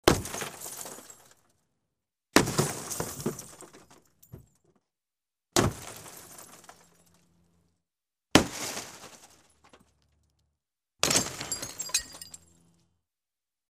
Звуки разбитого стекла, посуды
На этой странице собраны разнообразные звуки разбитого стекла и посуды: от легкого звона бокалов до резкого грохота падающих тарелок.